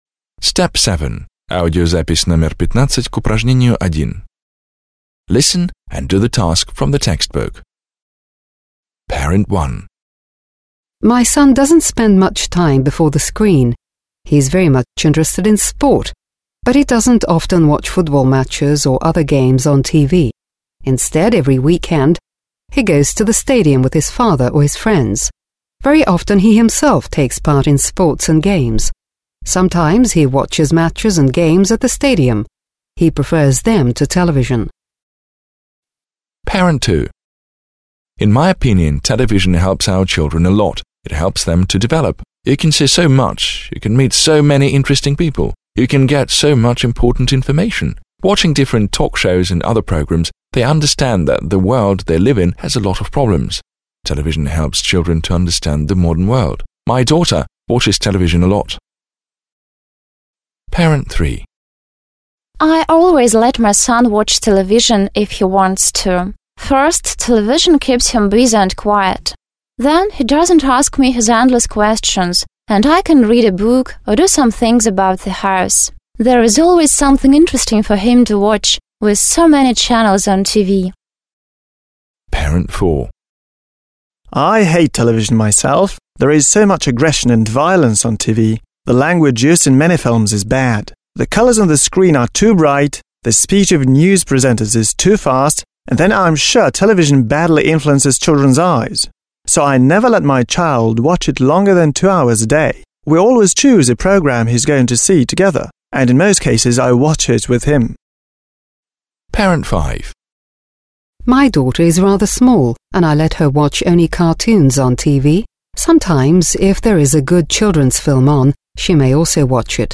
1. Вы услышите, как пять родителей говорят о телевидении и своих детях.